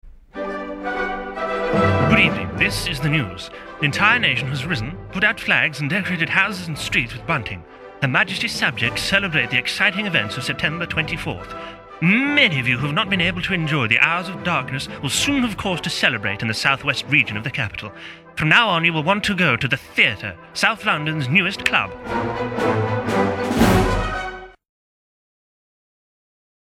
Voice Reel
Characterful, Humorous, Posh